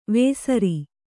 ♪ vēsari